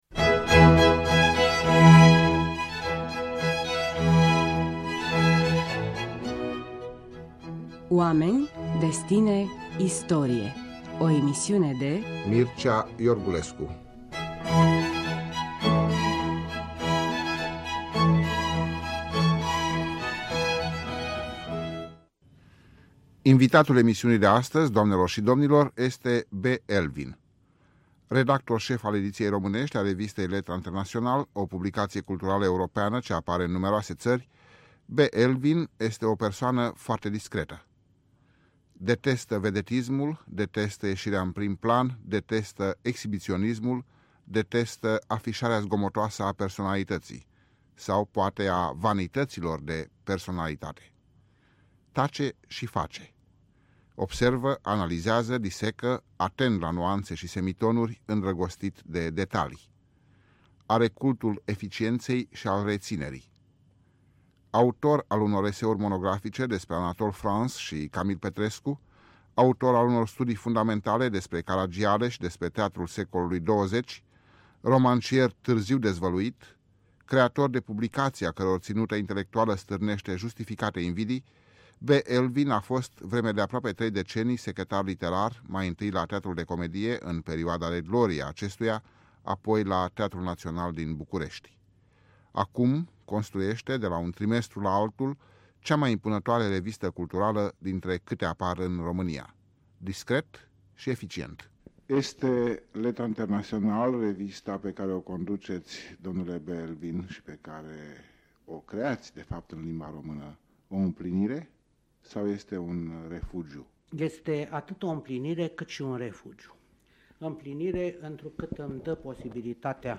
O discuție despre evoluția unui intelectual în anii comunismului și după.